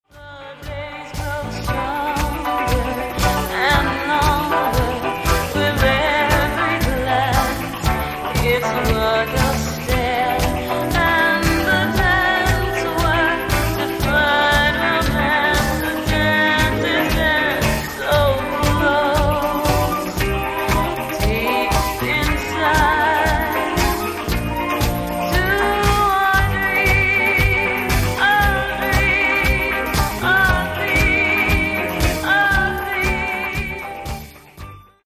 Genere:   Disco Soul